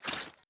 Throw5.wav